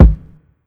Town Kick .wav